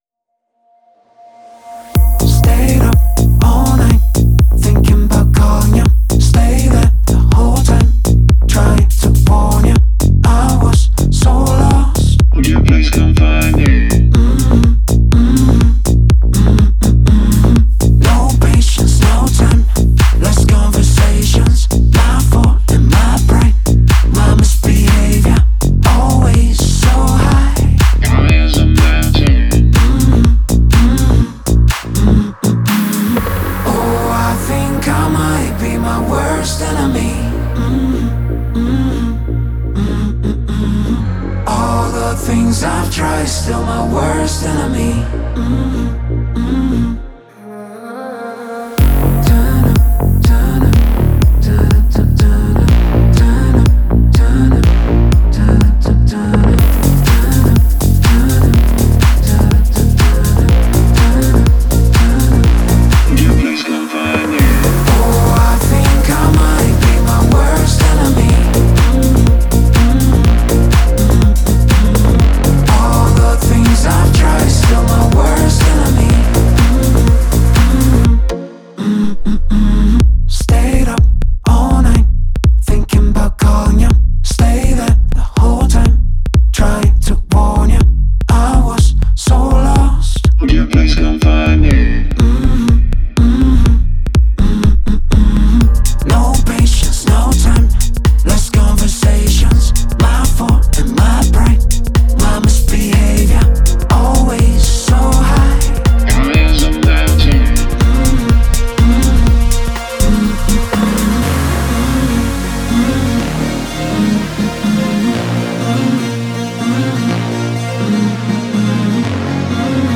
это динамичная трек в жанре EDM